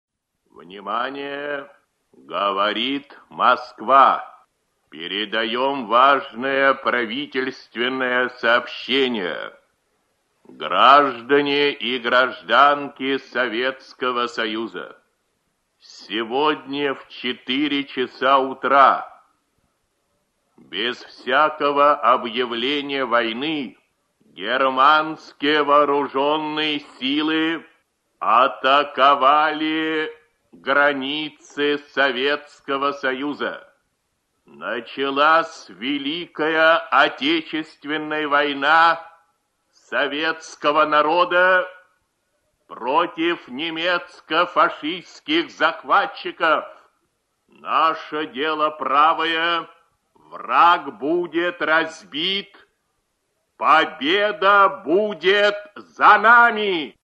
Голос Левитана.